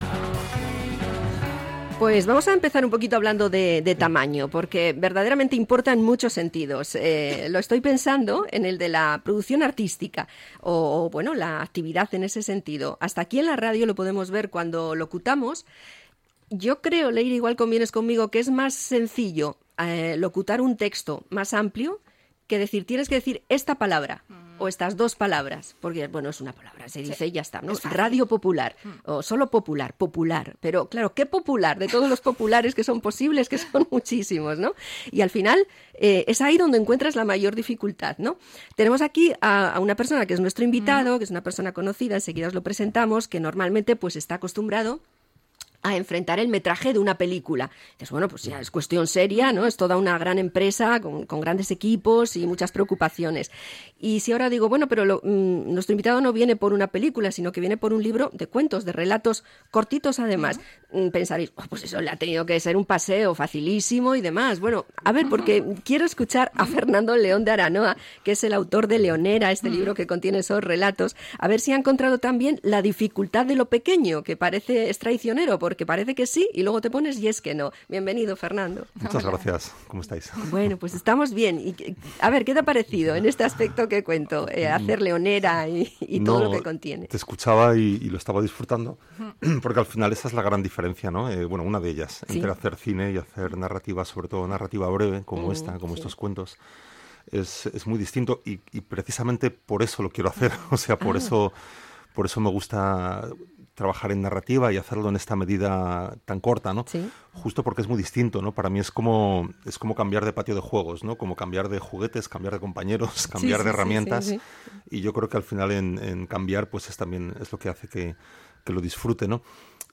Entrevista al director de cine y escritor Fernando León de Aranoa